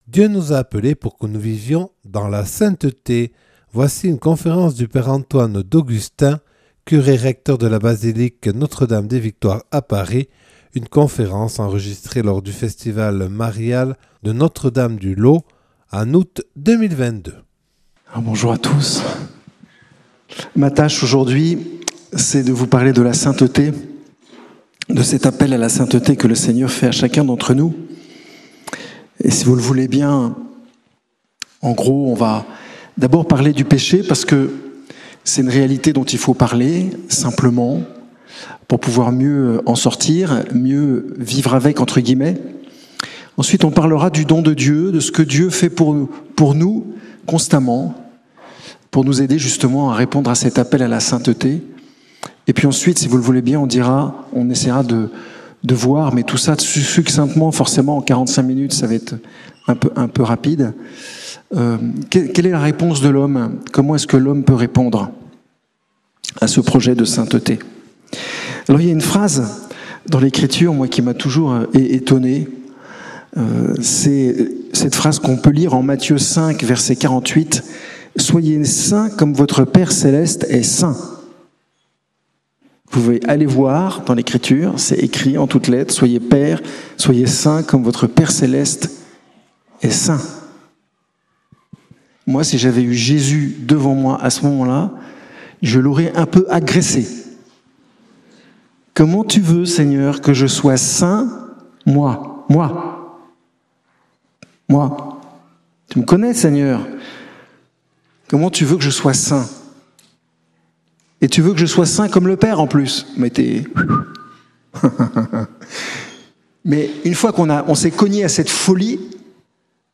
Une conférence
Enregistré en août 2022 lors du Festival marial de Notre Dame du Laus